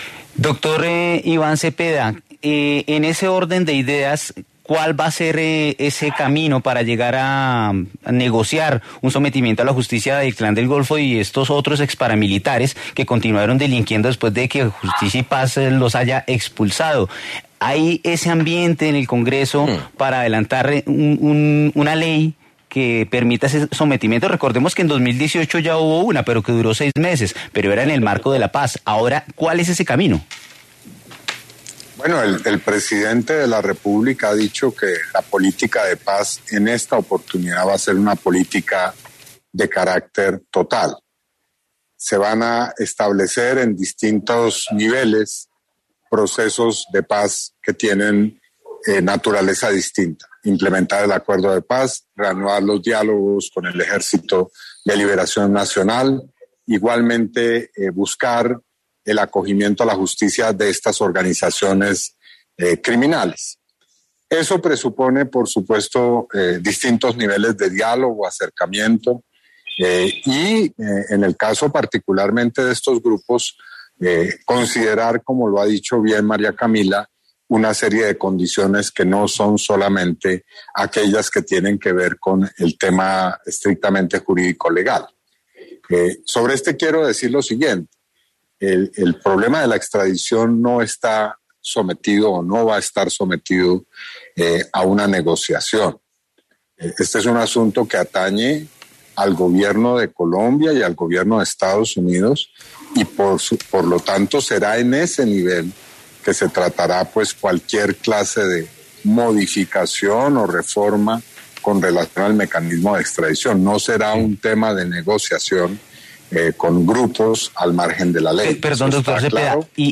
Iván Cepeda, senador por el Pacto Histórico, habló en Sigue La W a propósito de la carta enviada por el Clan del Gofo y exparamilitares a Gustavo Petro.